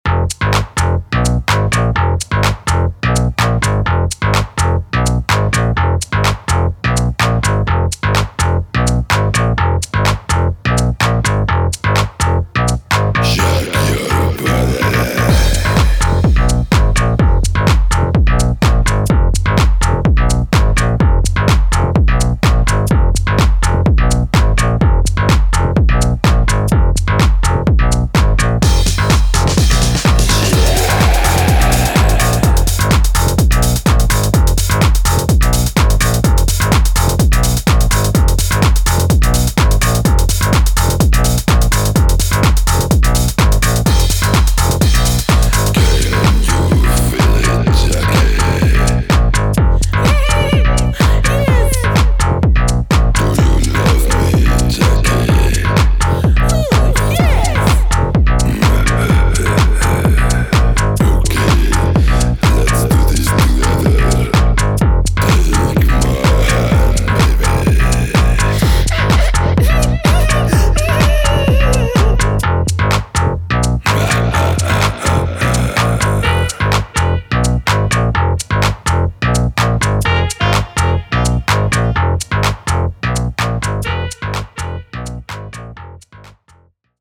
【12"INCH】(レコード)
ジャンル(スタイル) NU DISCO / HOUSE